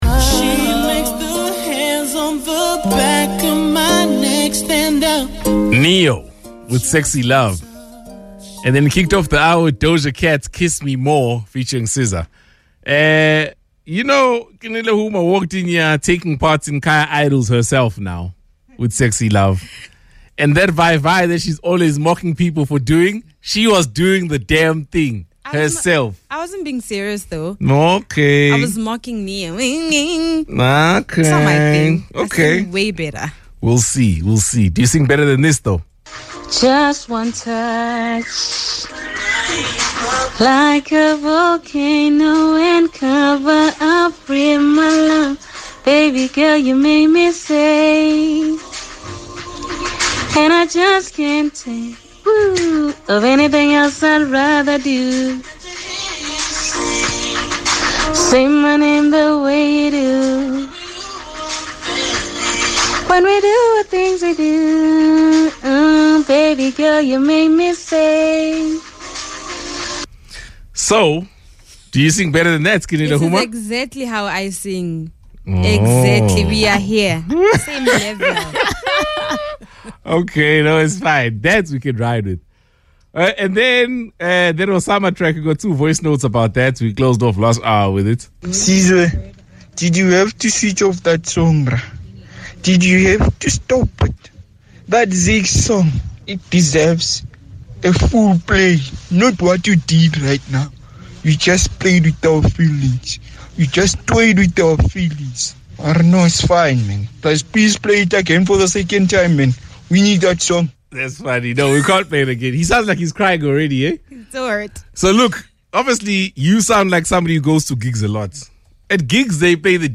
Naturally, the team were split on this contentious topic with the girls against the guys in this heated debate.